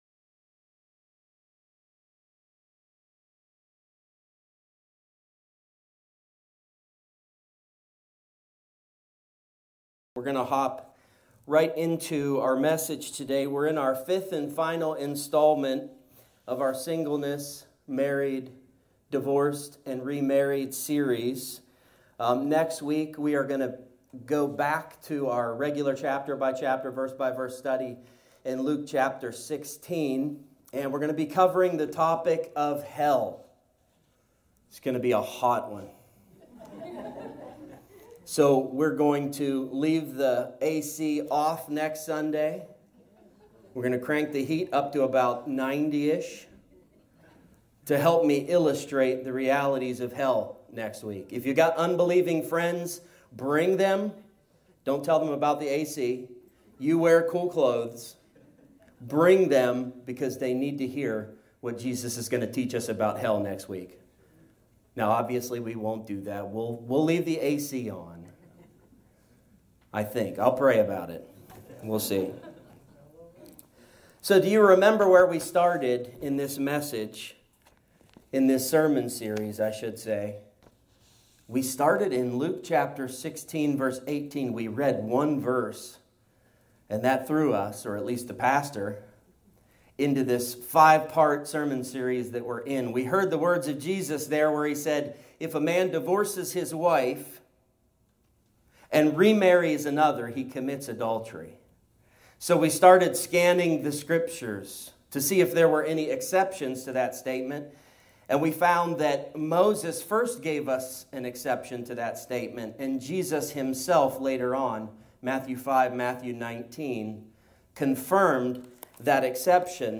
A topical teaching on the subjects of singleness, marriage, divorce, and remarriage.